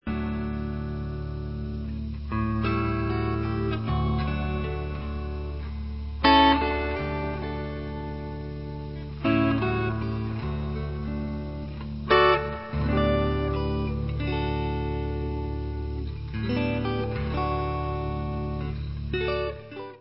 sledovat novinky v oddělení Blues